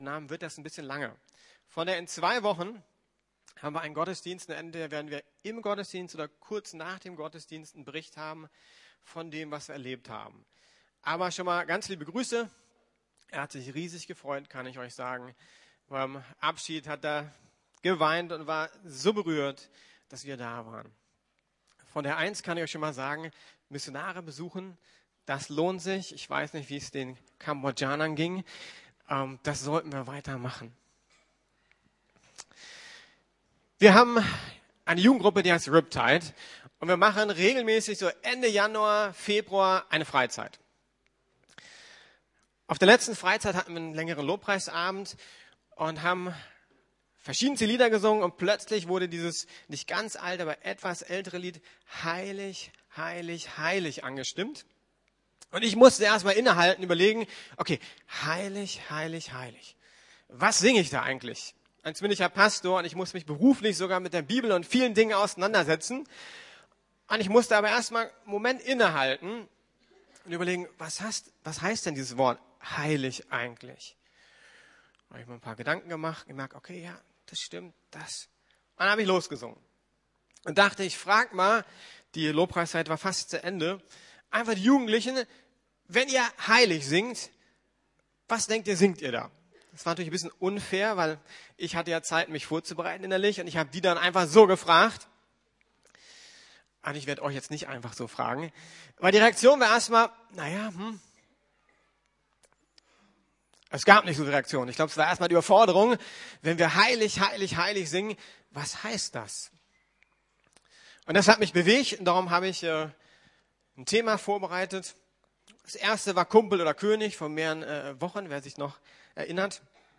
Geboren um heilig zu sein! ~ Predigten der LUKAS GEMEINDE Podcast